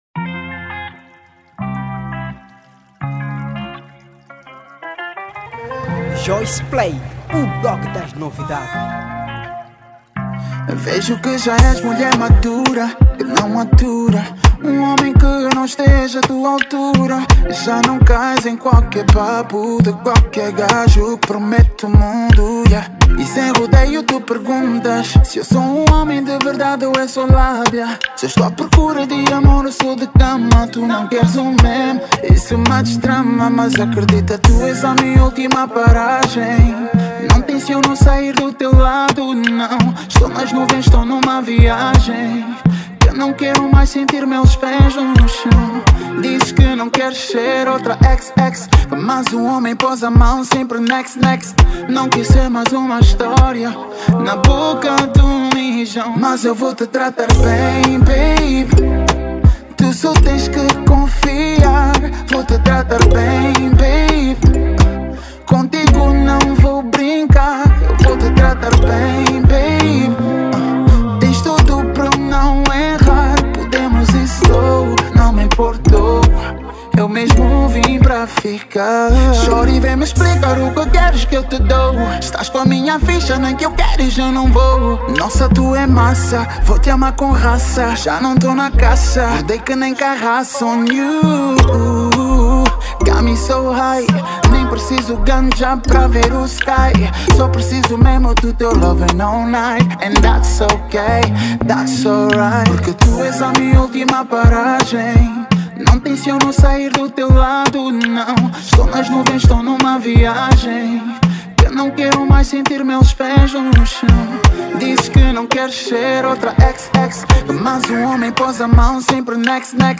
Género: R&B